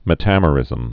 (mĭ-tămə-rĭzəm)